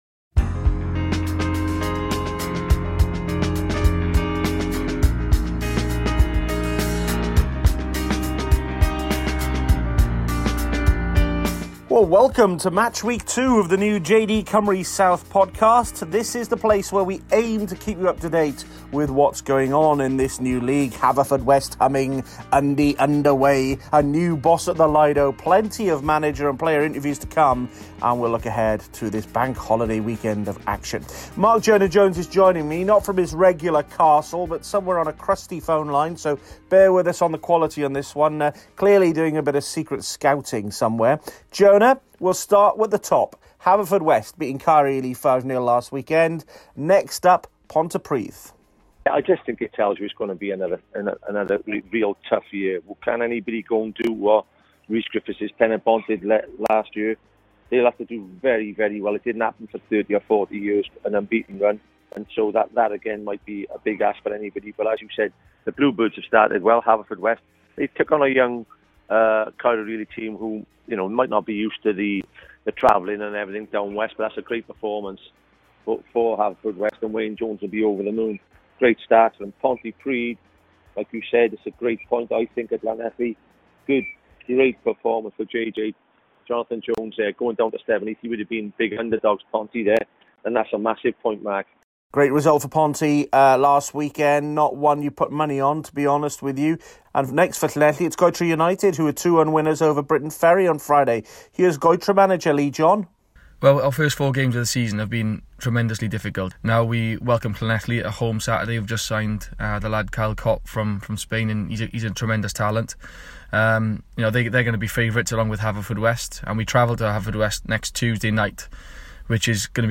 Interviews from Goytre, Afan Lido, Cwmamman, Cwmbran and Swansea University